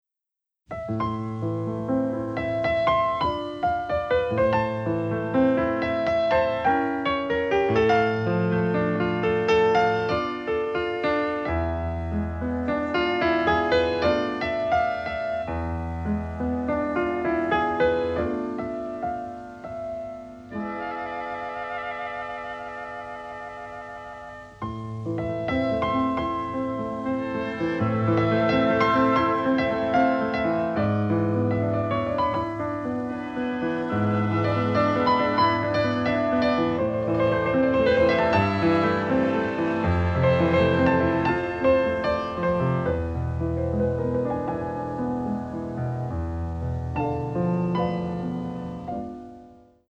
and the resulting sound quality is very much improved.